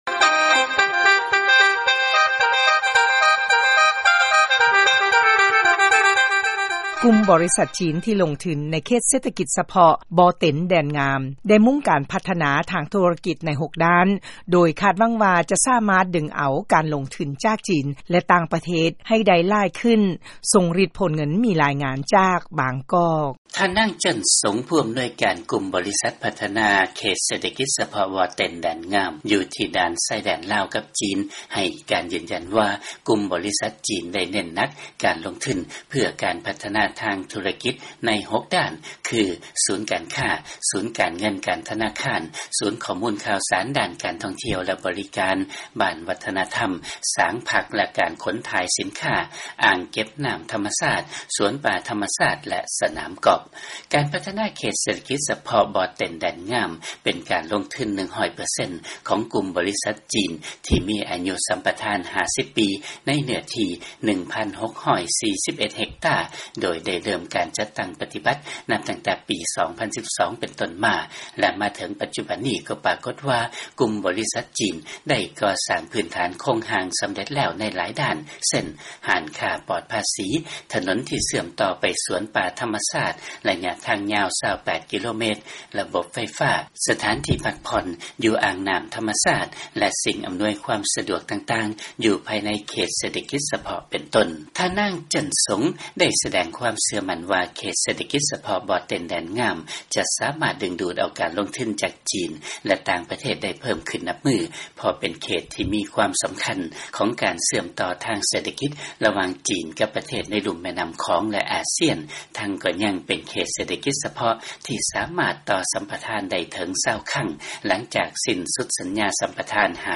ຟັງລາຍງານ ກຸ່ມບໍລິສັດຈີນ ລົງທຶນ ໃນເຂດ ເສດຖະກິດ ສະເພາະ ບໍ່ເຕັນແດນງາມ ໃນລາວ